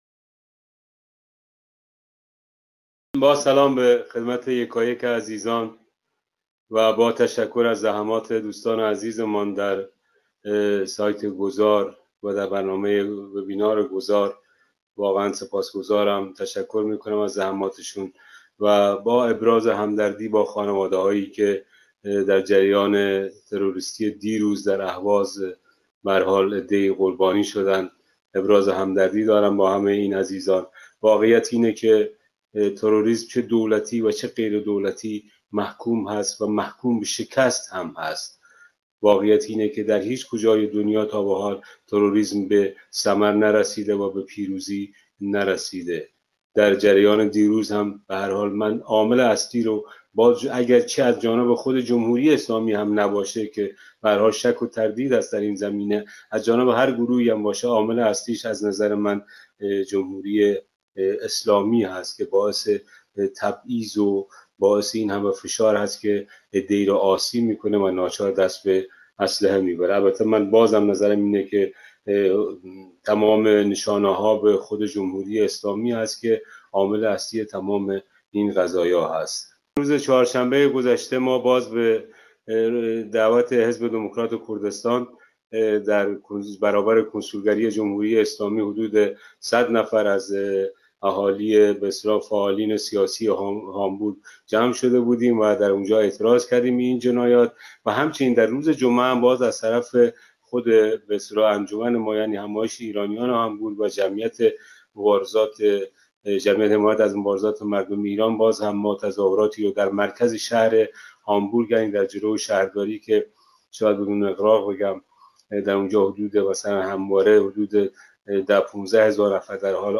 به گزارش گذار (سامانه دموکراسی و داد)، وبینار سراسری گذار از استبداد اسلامی به دموکراسی، با موضوع راهکارهای میدانی برای گذار خشونت پرهیز از استبداد اسلامی به دموکراسی، در جهت حمایت و شرکت فعال ایرانیان خارج از کشور از جنبش اعتراضی برای تغییرات بنیادی در حاکمیت سیاسی، با حضور جمعی از کنشگران مدنی، صاحب‌نظران و فعالان سیاسی، شامگاه یک‌شنبه ۲۳ سپتامبر ۲۰۱۸ (۱ مهر ۱۳۹۷) ساعت ۲۱:۳۰ به وقت ایران برگزار شد.